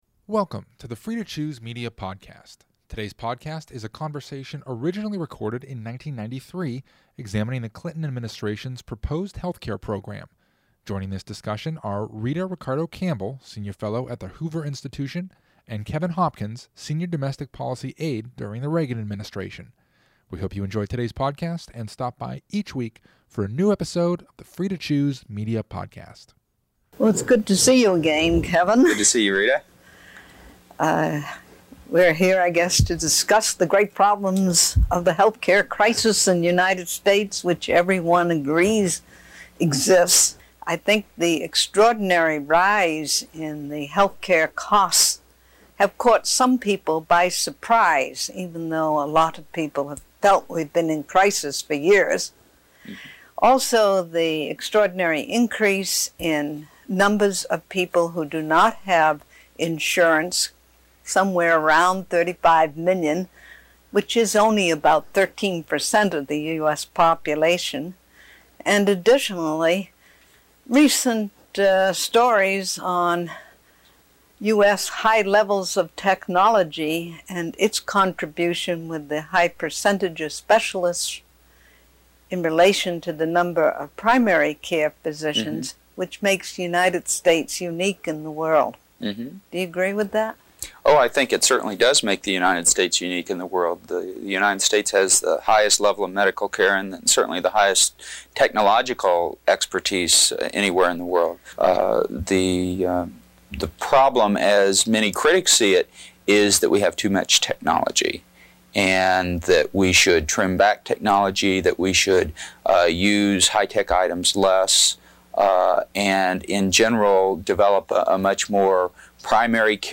Originally Recorded: 1993